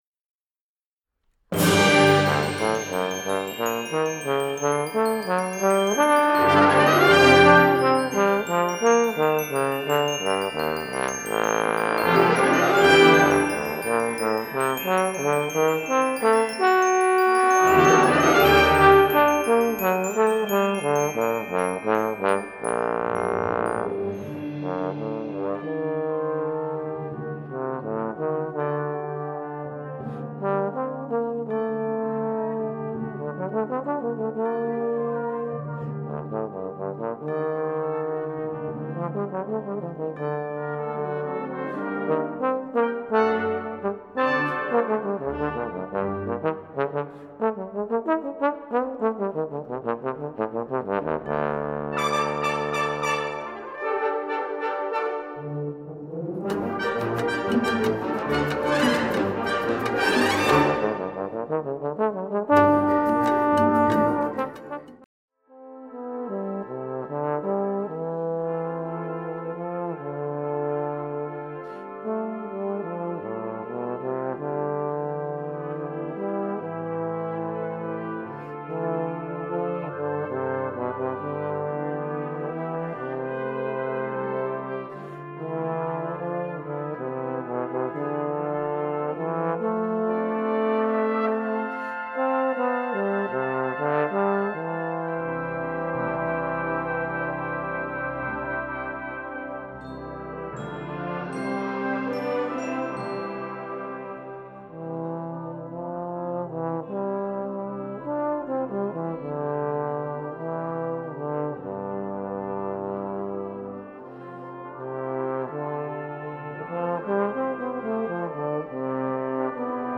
Bass Trombone Solo
Timpani
Mallets